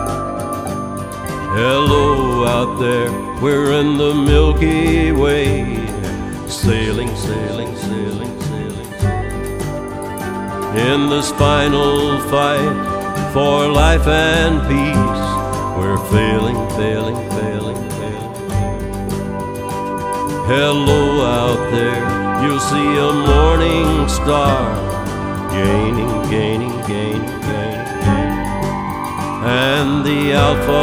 Guitare
country music